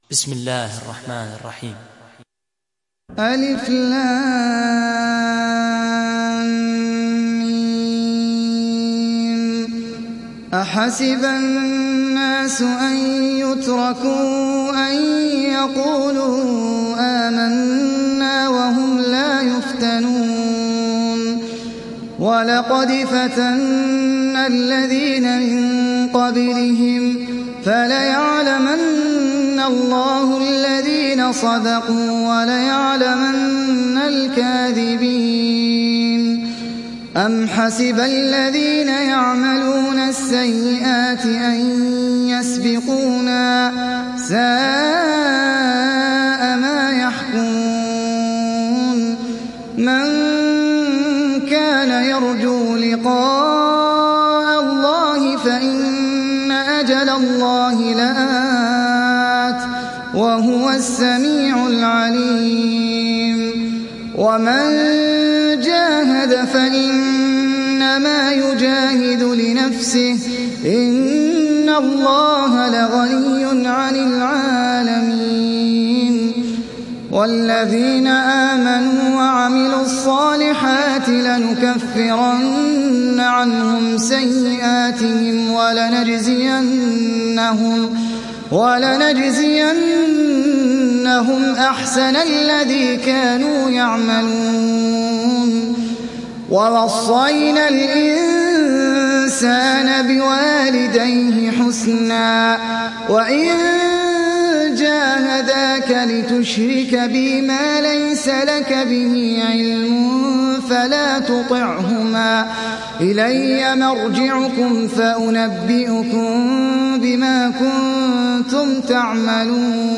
Ankebut Suresi İndir mp3 Ahmed Al Ajmi Riwayat Hafs an Asim, Kurani indirin ve mp3 tam doğrudan bağlantılar dinle